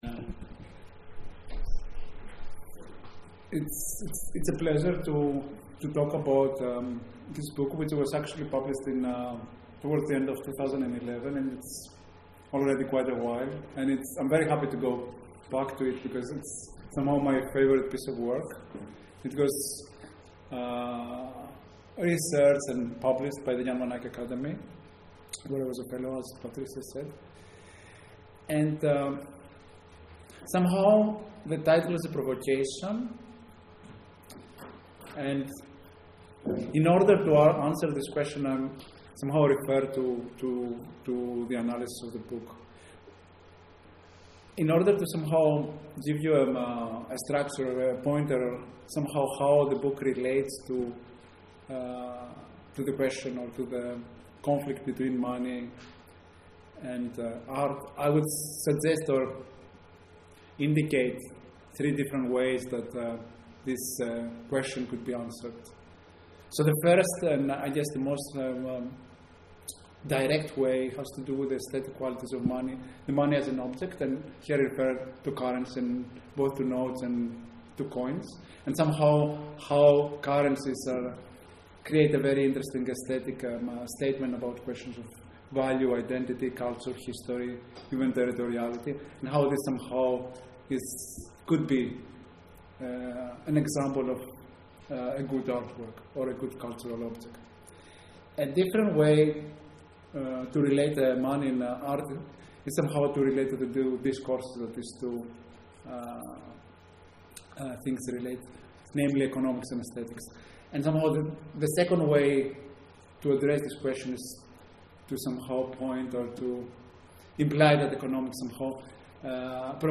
Talks
Or Gallery Berlin